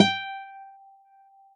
guitar-nylon
G5.wav